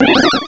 cry_not_mantyke.aif